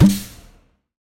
etfx_shoot_gas.wav